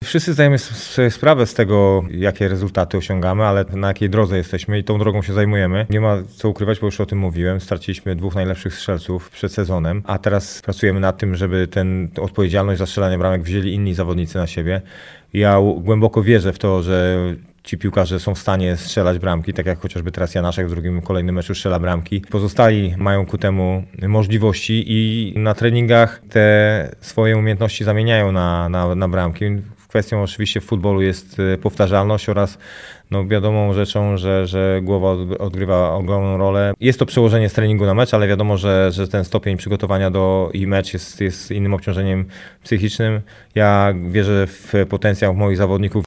Sytuację komentuje trener Górnika Maciej Stolarczyk.